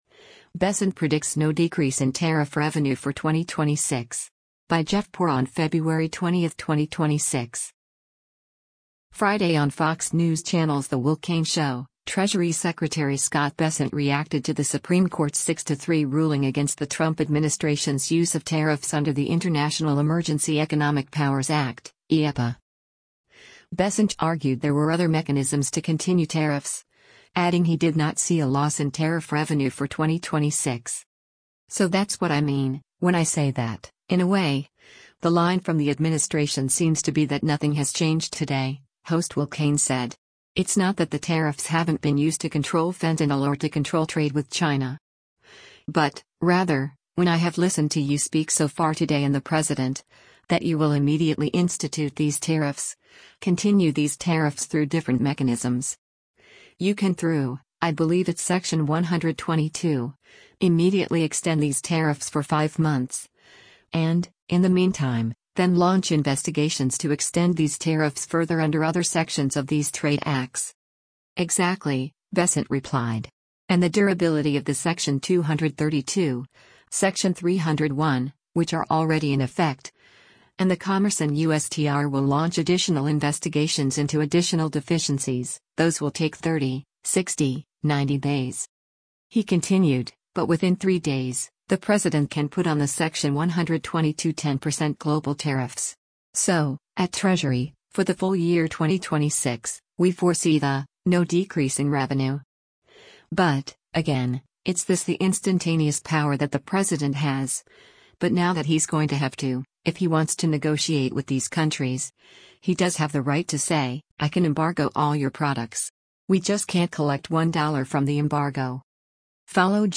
Friday on Fox News Channel’s “The Will Cain Show,” Treasury Secretary Scott Bessent reacted to the Supreme Court’s 6-3 ruling against the Trump administration’s use of tariffs under the International Emergency Economic Powers Act (IEEPA).